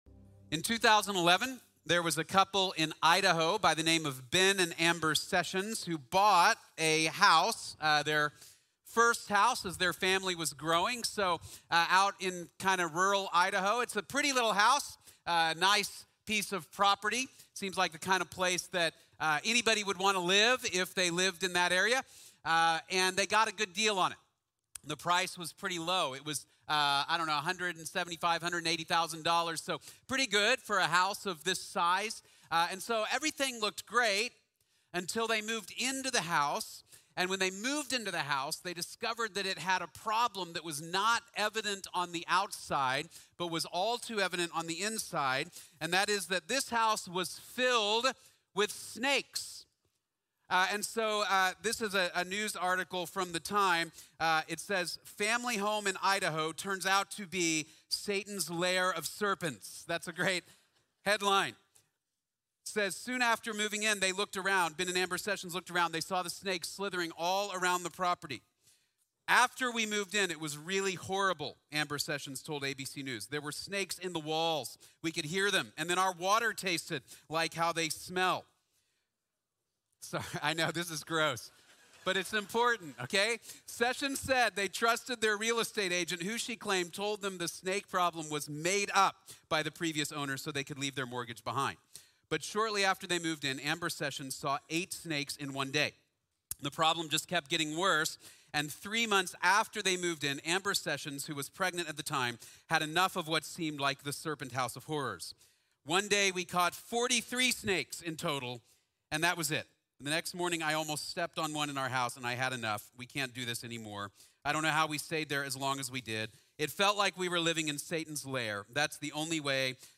La imagen de la salud | Sermón | Iglesia Bíblica de la Gracia